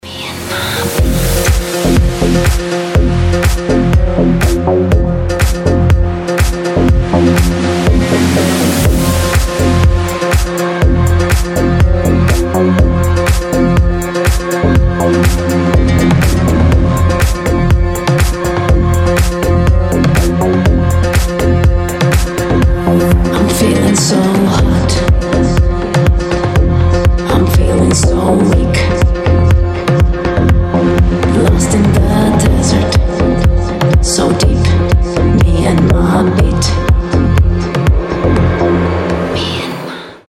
• Качество: 320, Stereo
женский вокал
deep house
восточные мотивы
расслабляющие